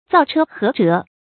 造车合辙 zào chē hé zhé
造车合辙发音
成语注音ㄗㄠˋ ㄔㄜ ㄏㄜˊ ㄓㄜˊ